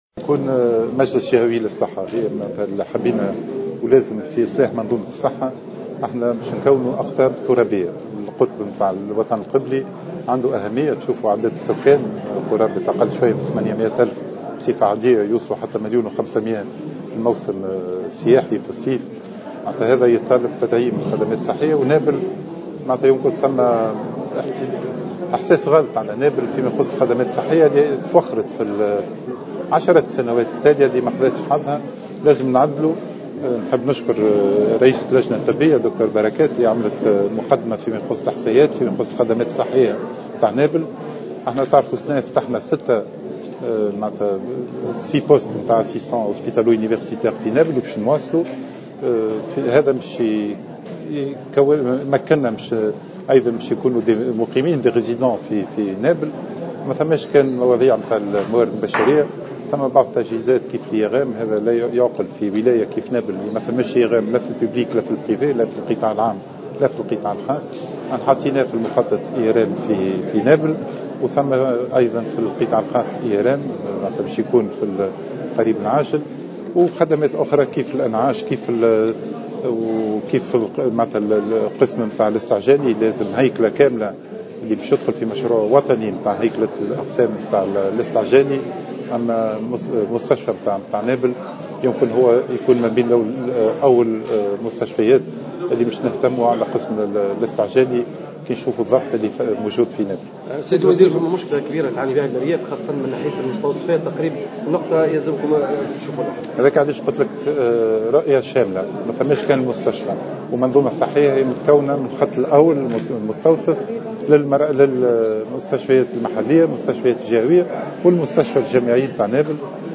وأفاد في تصريح اعلامي على هامش الزيارة التي اداها الى عدد من المؤسسات الصحية بولاية نابل، بأن الوزارة انطلقت بعد في تفعيل مجموعة من الاجراءات العاجلة التي تهدف الى النهوض بالمنظومة الصحية وتحسين الخدمات وتقريبها من مستحقيها من بينها بالخصوص تغطية الجهات التي تشكو نقصا باطباء الاختصاص.